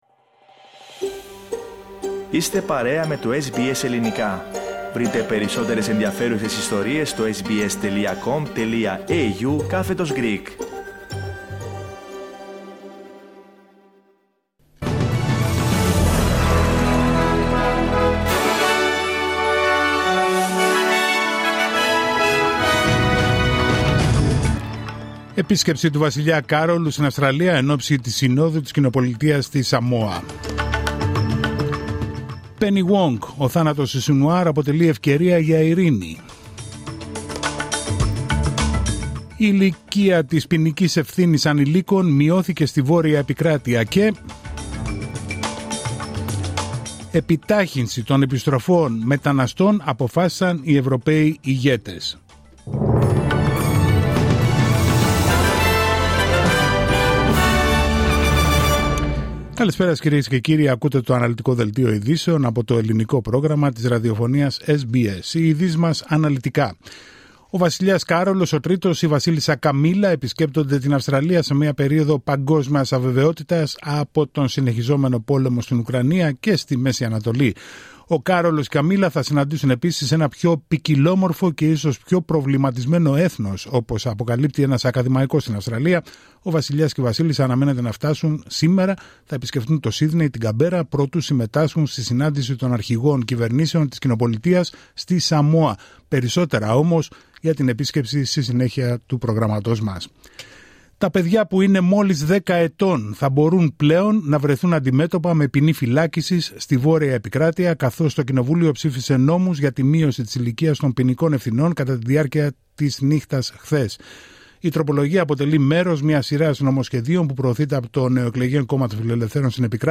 Δελτίο ειδήσεων Παρασκευή 18 Οκτωβρίου 2024